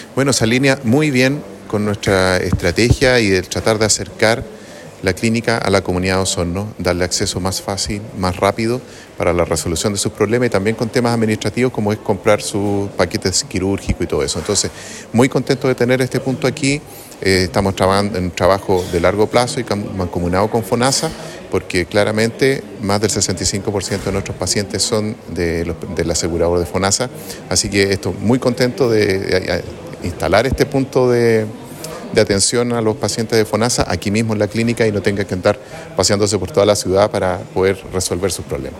La inauguración oficial de este nuevo Punto de Atención Fonasa se realizó con un evento encabezado por directivos de la clínica y representantes de Fonasa.